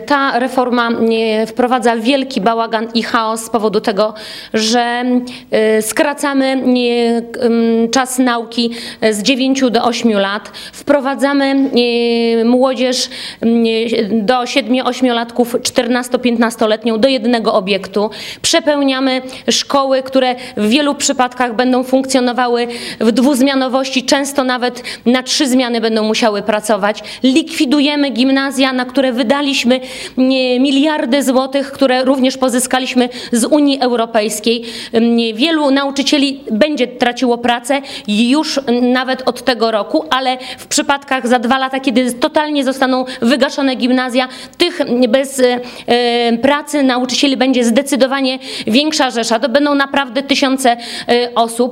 Na zorganizowanej w biurze poselskim konferencji prasowej zachęcały do wspierania tej akcji.